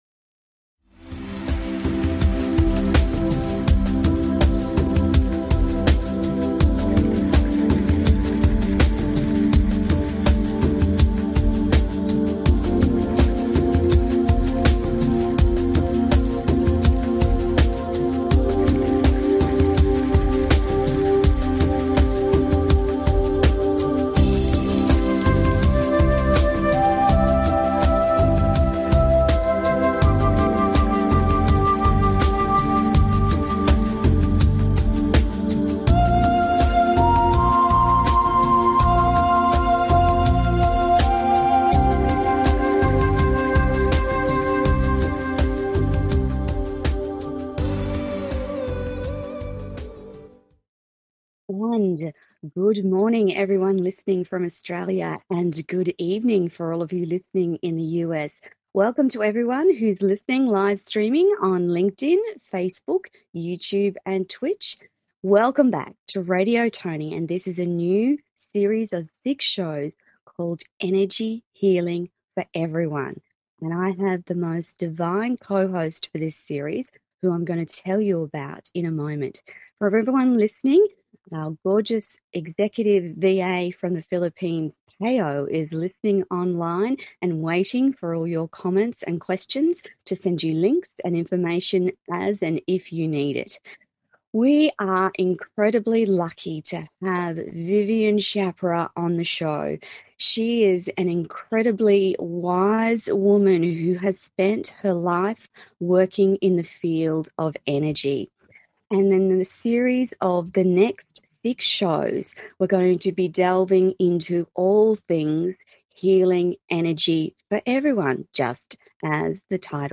Welcome and intro – new 6 show series; Listening live on FB, LI website, like subscribe and email